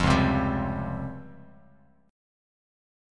C#M#11 chord